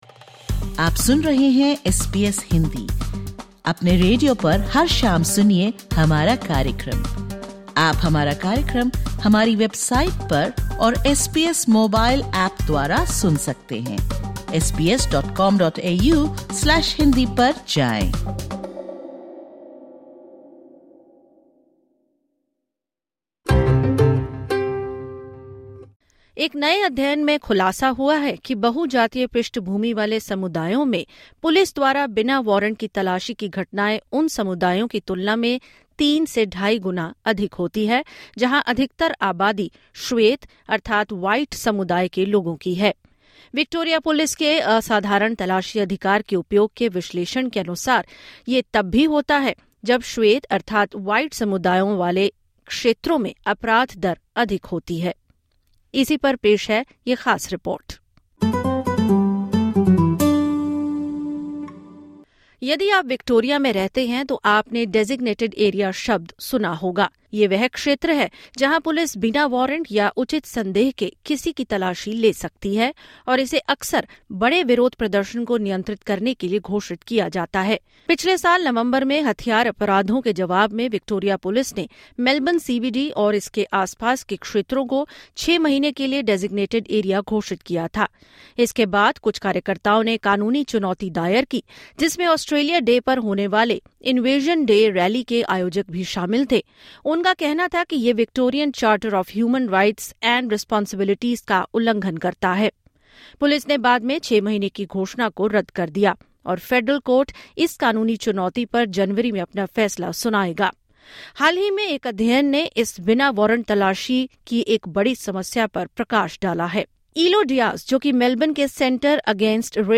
Disclaimer: The views presented in this interview are of general nature.